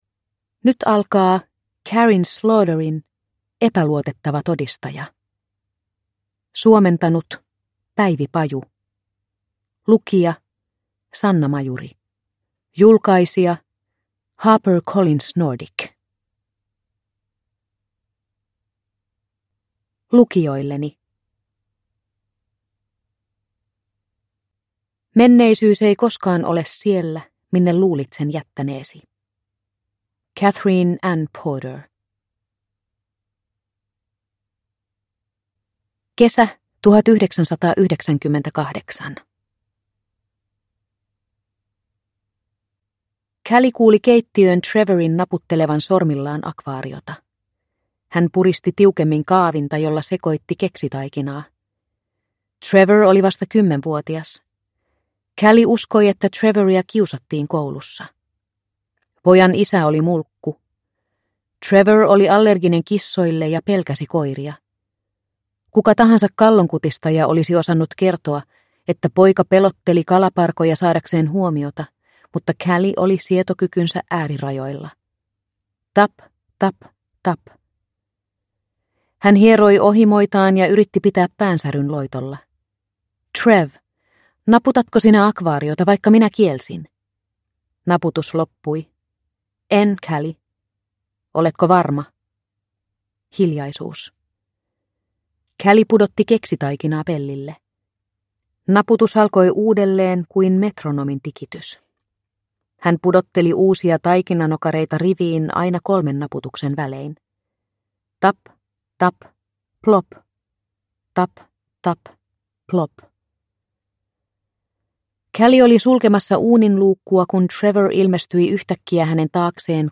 Epäluotettava todistaja – Ljudbok – Laddas ner